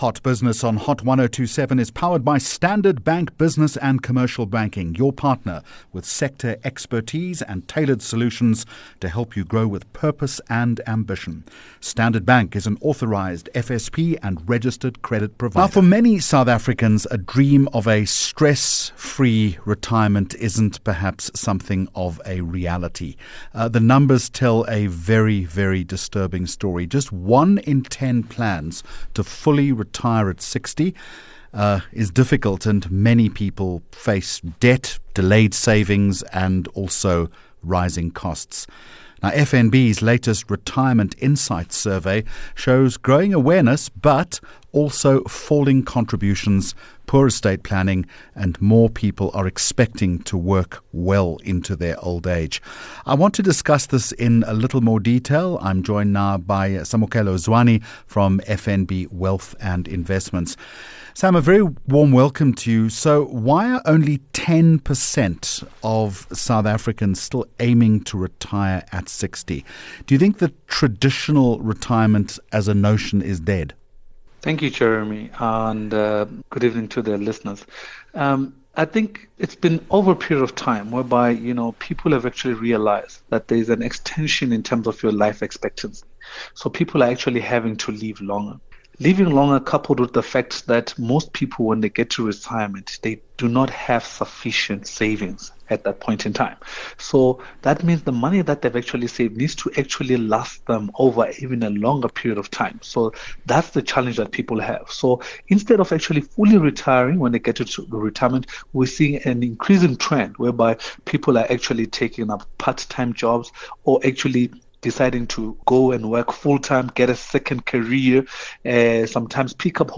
30 Jun Hot Business Interview